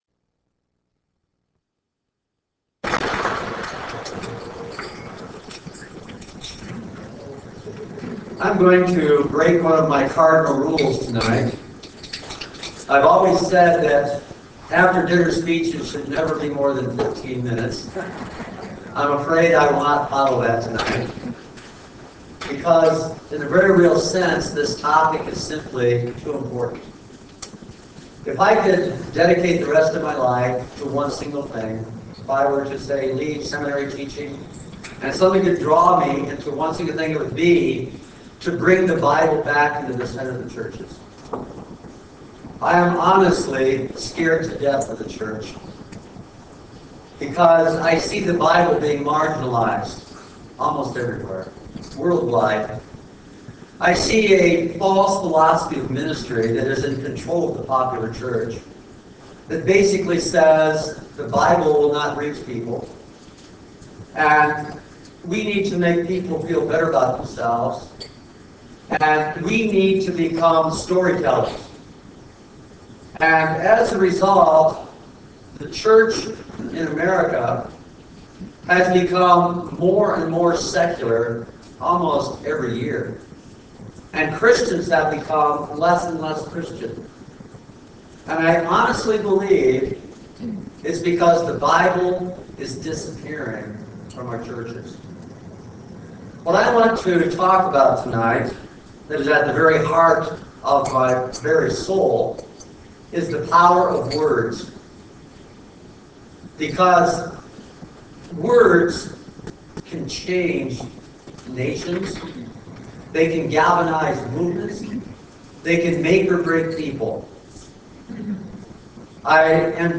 The Power & the Promise of the Word Conference